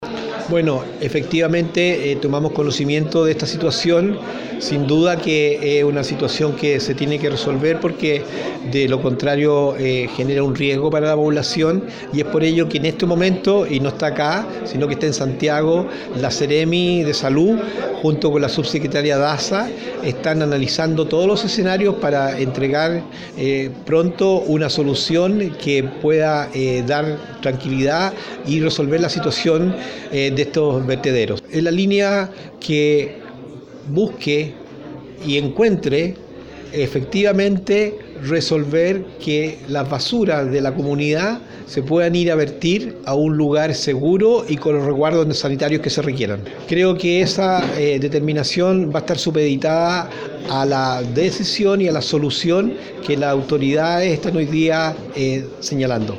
El ministro de Educación Emilio Santelices sostuvo que aún está en estudio la solicitud de las autoridades de Ancud de decretar Alerta Sanitaria en la comuna.